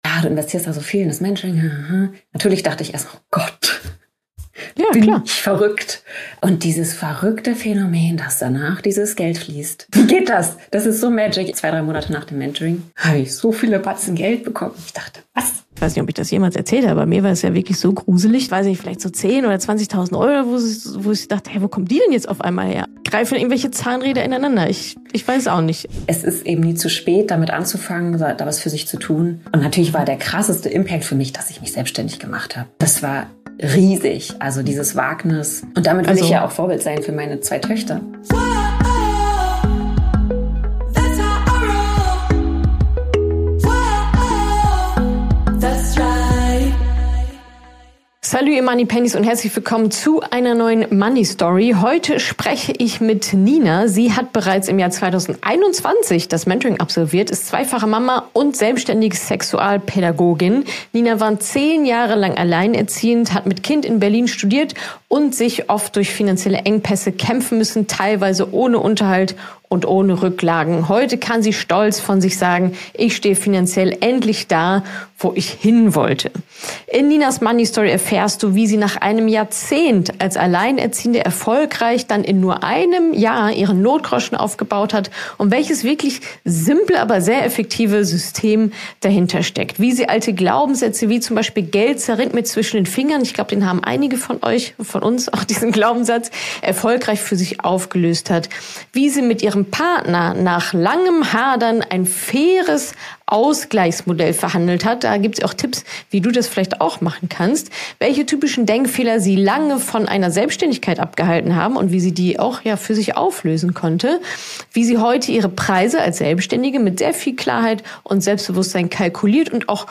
Sie verrät uns in diesem Gespräch, warum Frauen oft nicht führen wollen und sich – wenn sie dann doch führen – häufig nicht wohl damit fühlen.